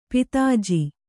♪ pitāji